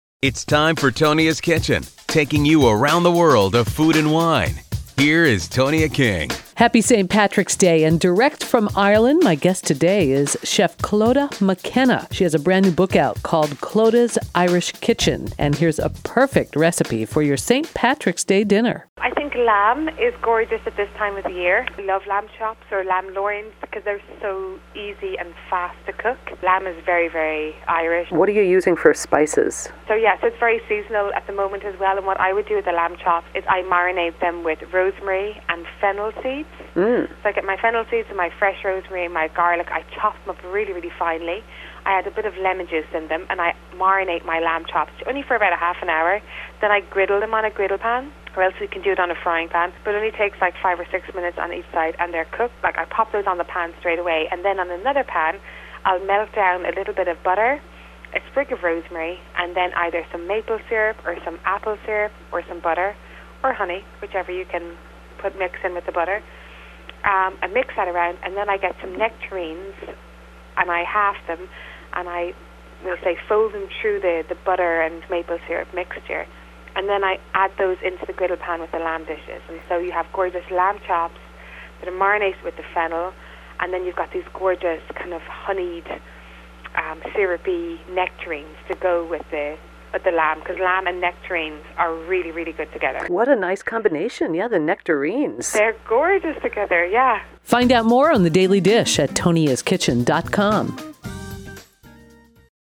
talked with Clodagh McKenna